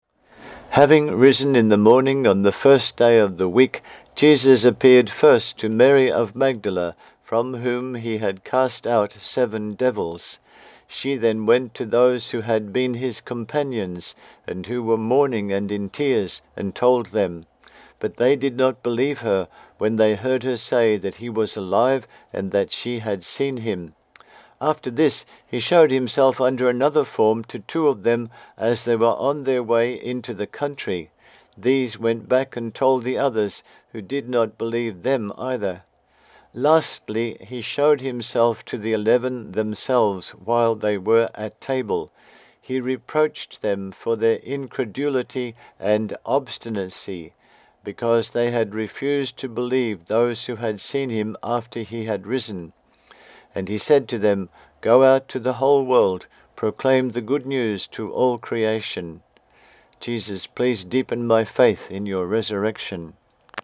4 readings + recordings